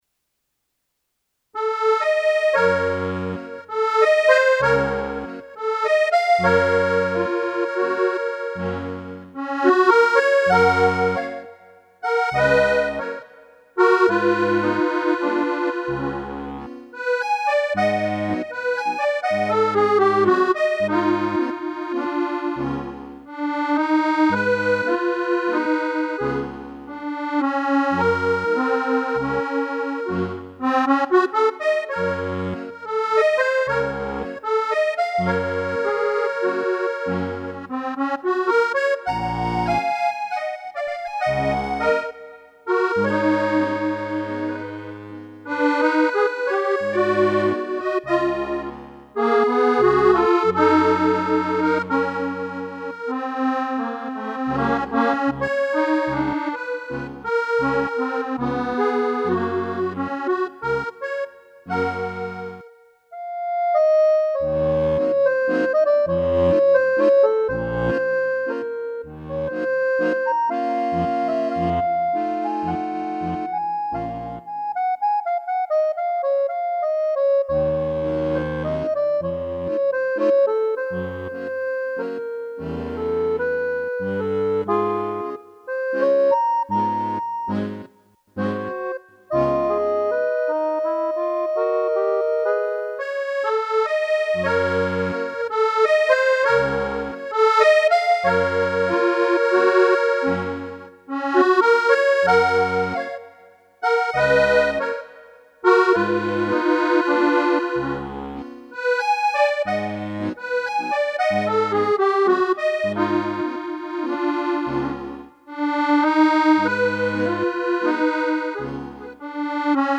Daraus jetzt ein Auszug (bitte entschuldigt die Aufnahme, sie ist grottenschlecht) Ich suche den Komponisten und den Titel des Werks, aus dem das Rätselstück stammt. your_browser_is_not_able_to_play_this_audio